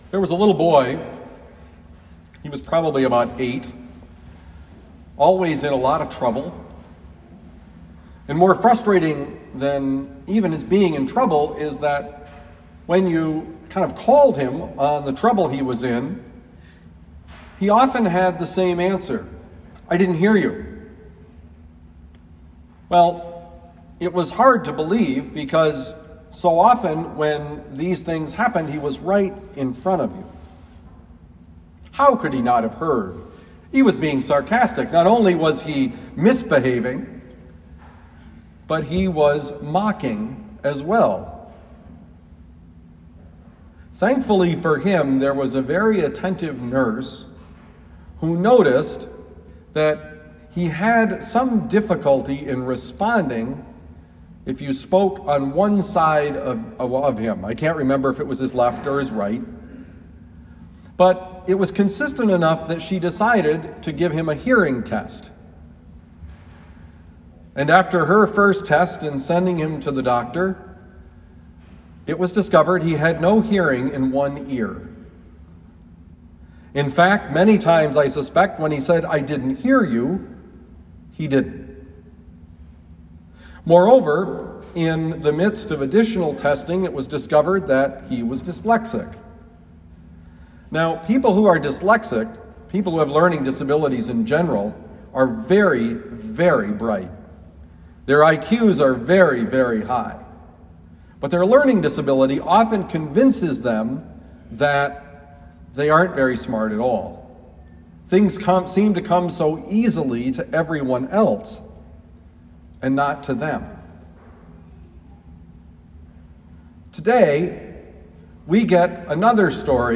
Homily for January 17, 2021
Given at Our Lady of Lourdes Parish, University City, Missouri.